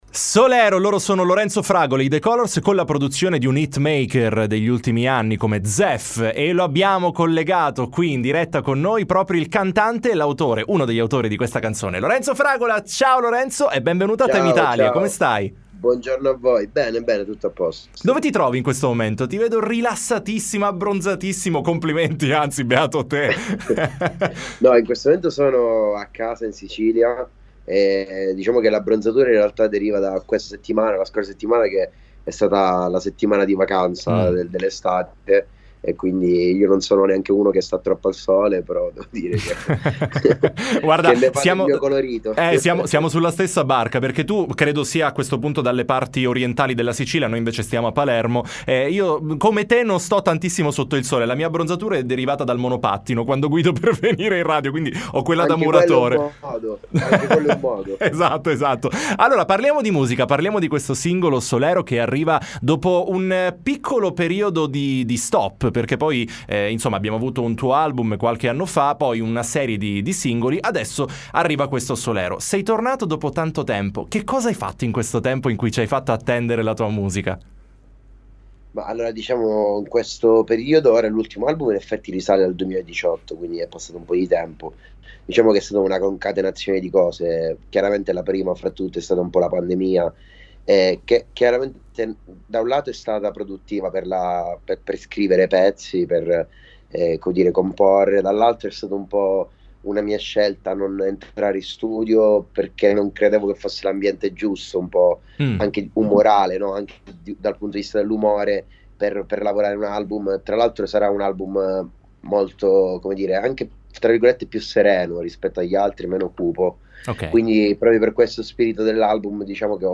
T.I. Intervista Lorenzo Fragola